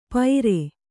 ♪ paire